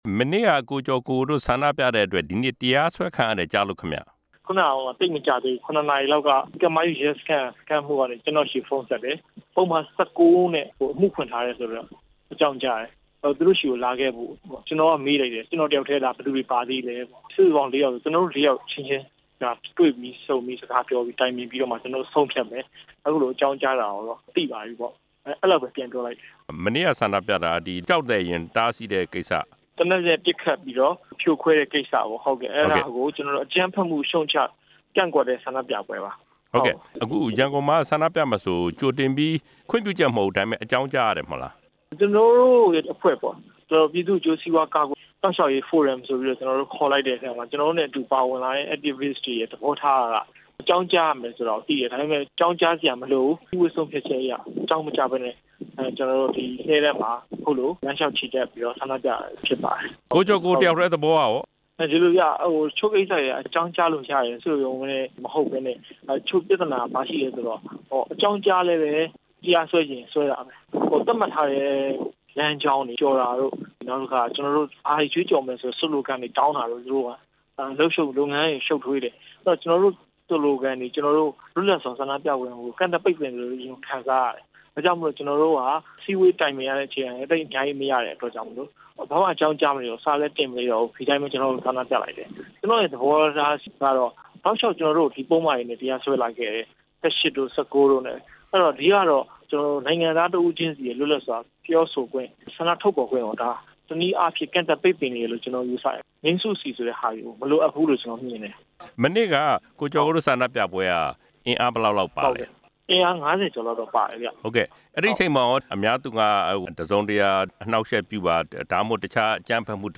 ဆက်သွယ်မေးမြန်းချက်မှာ နားဆင်နိုင်ပါတယ်။